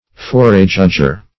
Meaning of forejudger. forejudger synonyms, pronunciation, spelling and more from Free Dictionary.
Search Result for " forejudger" : The Collaborative International Dictionary of English v.0.48: Forejudger \Fore*judg"er\, n. (Eng.